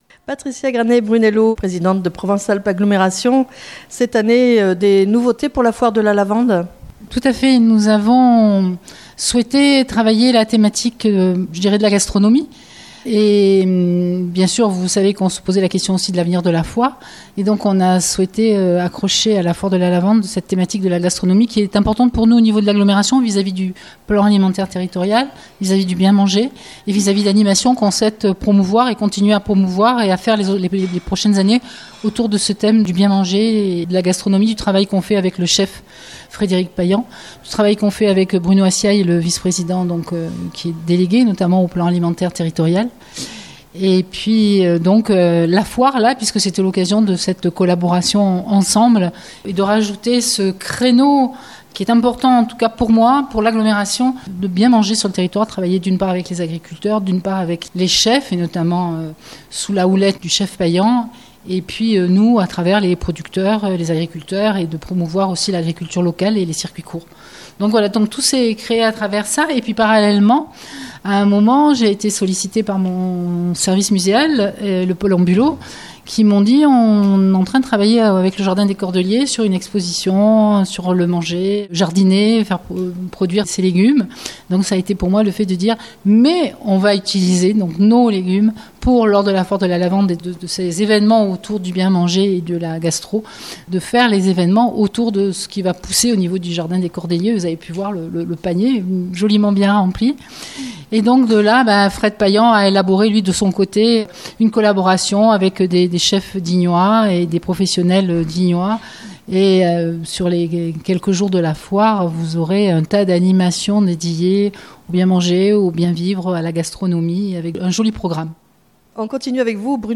Le jardin des Cordeliers à l'honneur pour cette 101ème Foire de la Lavande à Digne les bains. Dans ce reportage, vous entendrez successivement : - Patricia Granet-Brunello Présidente de Provence Alpes Agglomération et Maire de Digne les bains.